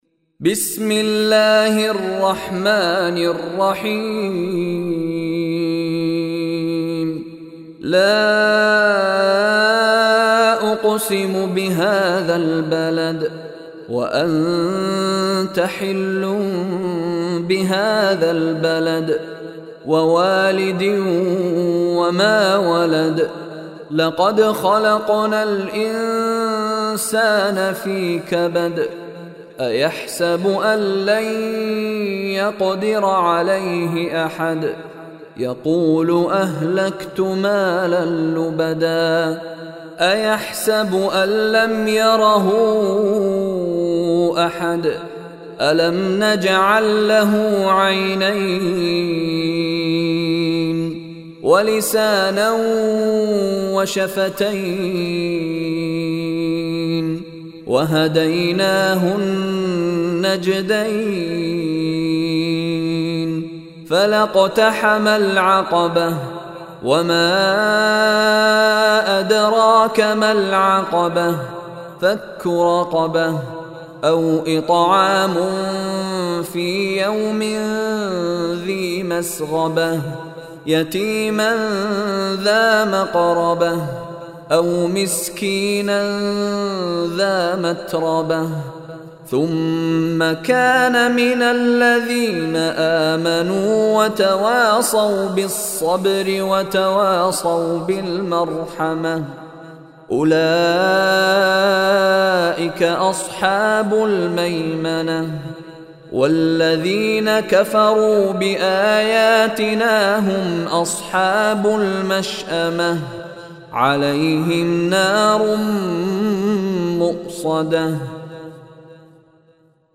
Surah Al-Balad Recitation by Mishary Rashid
Surah Al-Balad is 90 Surah / chapter of Holy Quran. Listen online and download beautiful tilawat / recitation of Surah Al-Balad in the voice of Sheikh Mishary Rashid Alafasy.